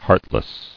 [heart·less]